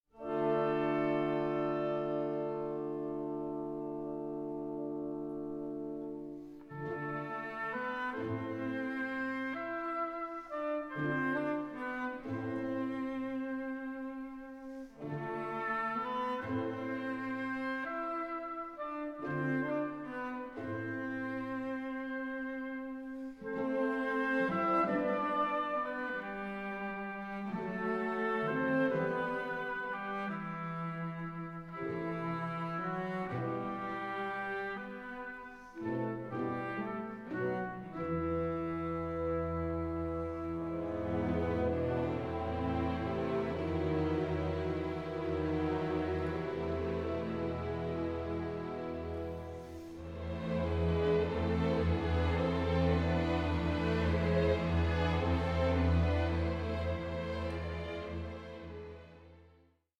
Adagio 5:34